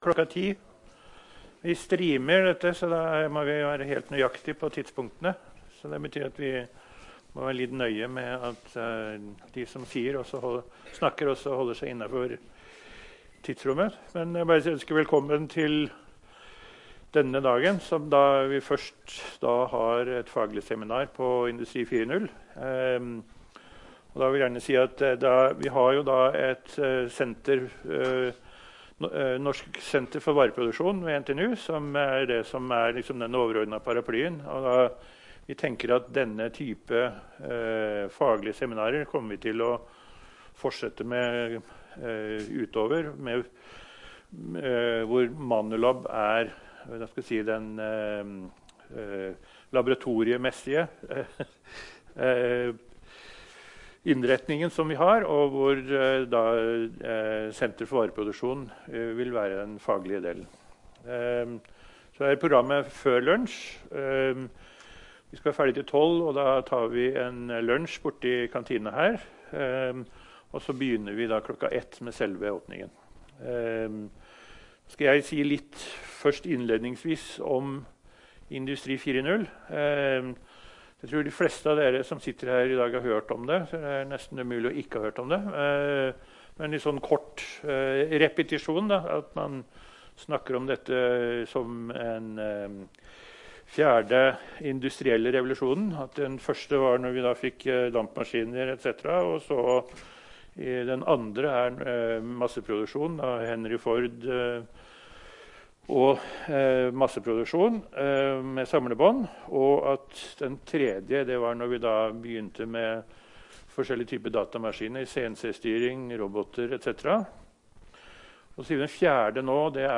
I forbindelse med offisiell åpning av Manulab